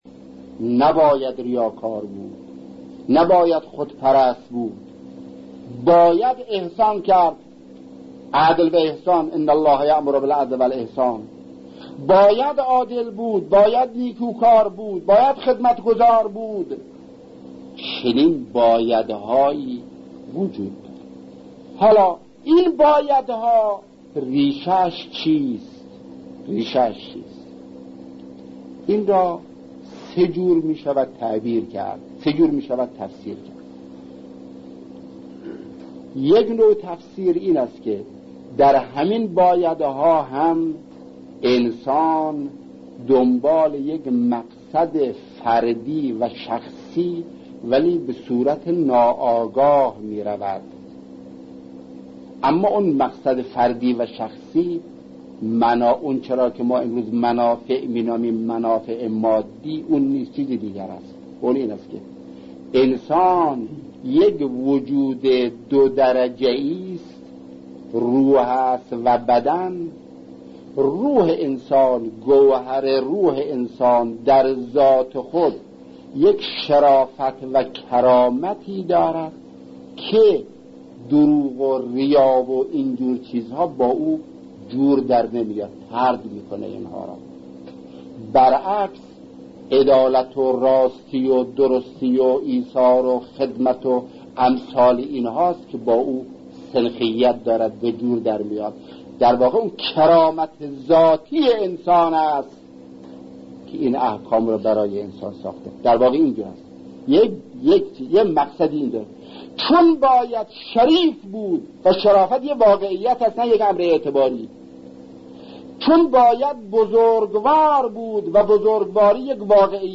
به گزارش ایکنا؛ کانال تلگرامی بنیاد شهید مطهری، اقدام به انتشار فایل صوتی سخنرانی شهید مرتضی مطهری پیرامون رابطه باید‌ها و نباید‌های اخلاقی و مسئله فطرت» کرده است.